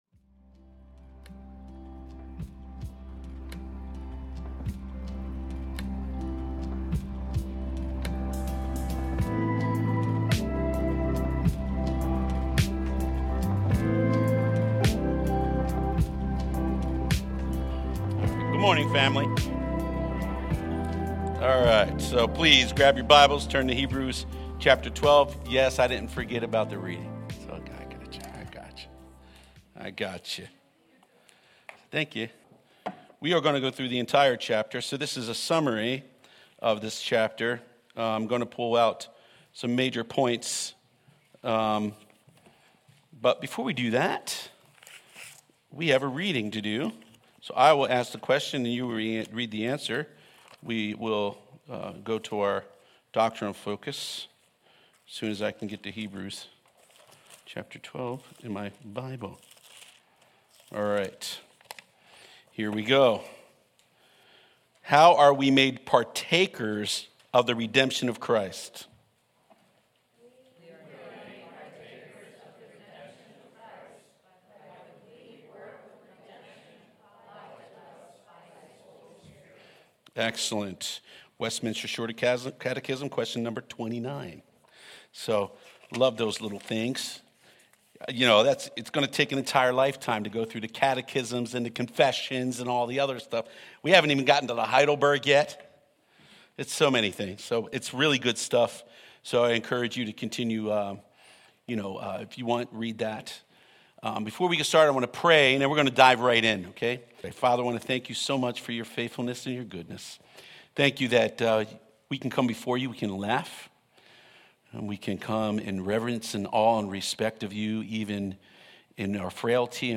Sermons | Mount Eaton Church